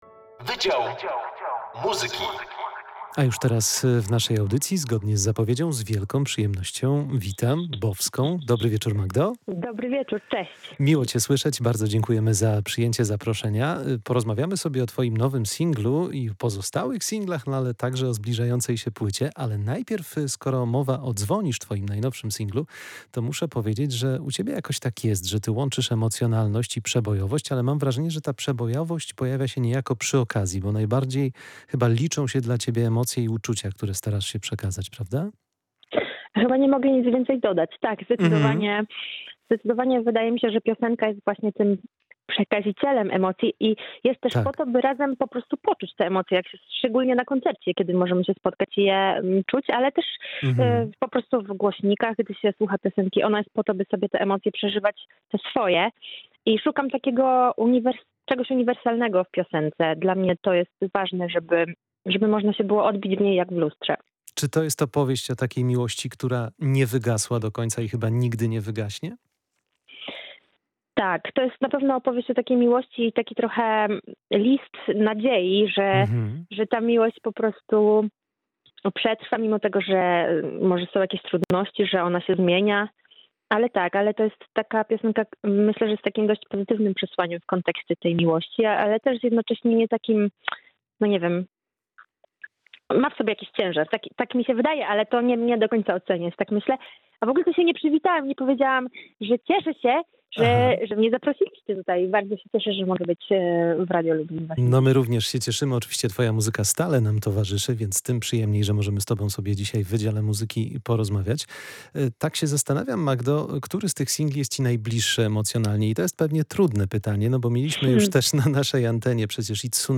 Wydział Muzyki: Bovska o niewygasłej miłości [POSŁUCHAJ ROZMOWY]